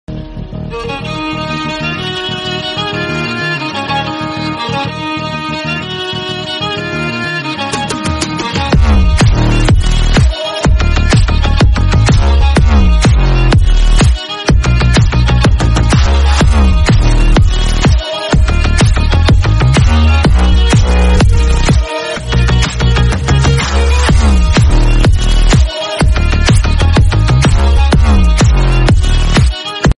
comedy song